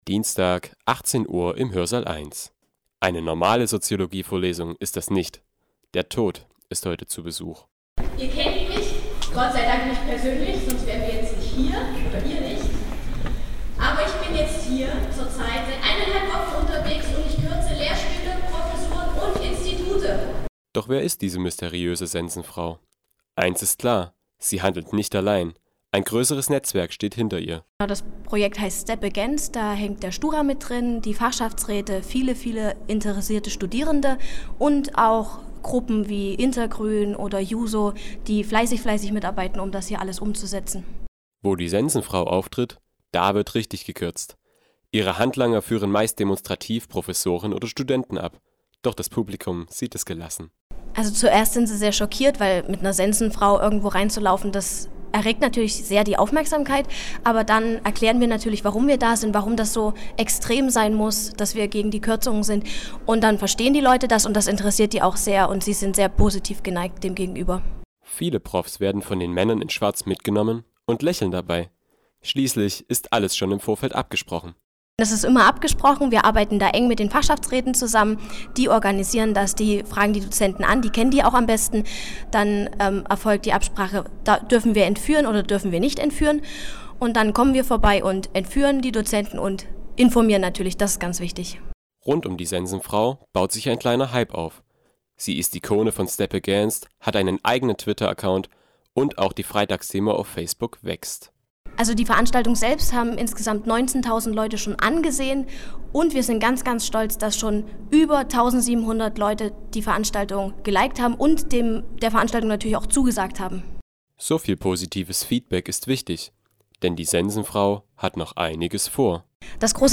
Interview mit dem Tod